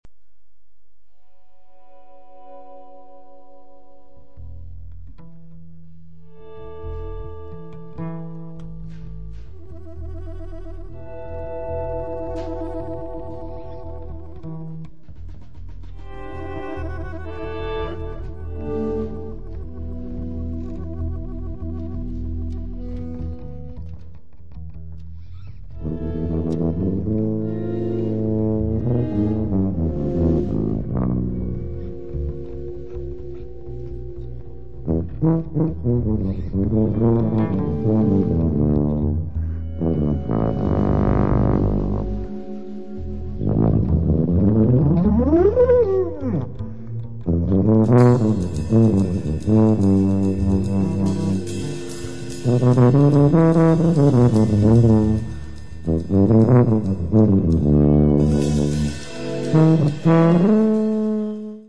tromba e flicorno
sax, clarinetto basso
trombone, tuba
bombardino
chitarra
contrabbasso
batteria
con la tuba a seguire la linea melodica